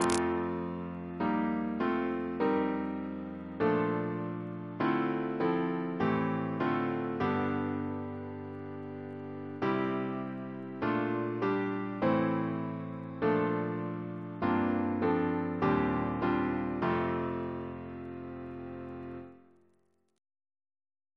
organ accompaniment
this chant unison